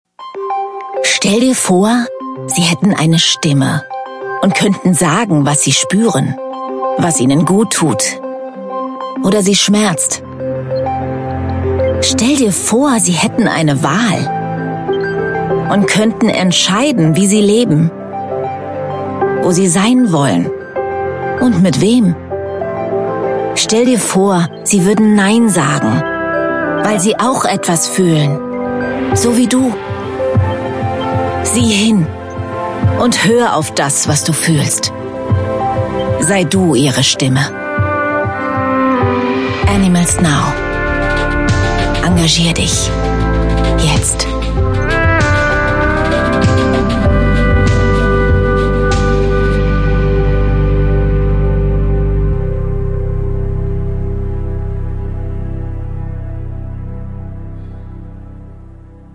TV Spot
Hörbuch